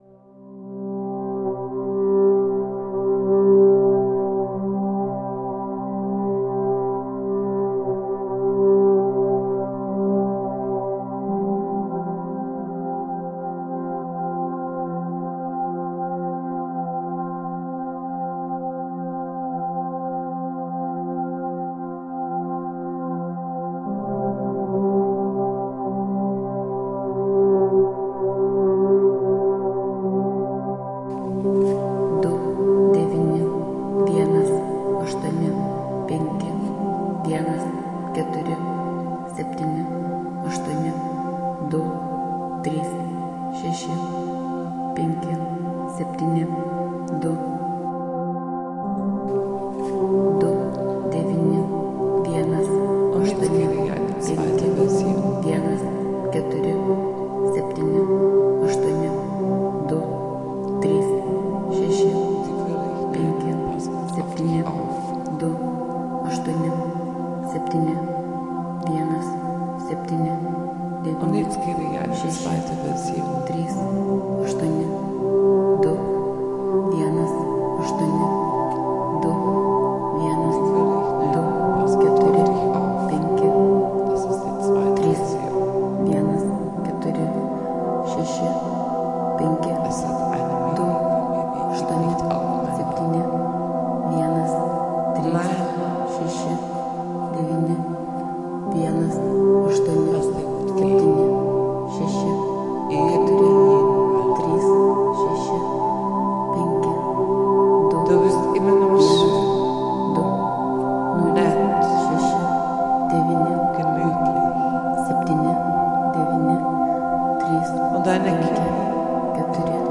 A very very calm ambient track for a sunday night. Recorded into SP404, this machine is magic, everything moves and comes alive!!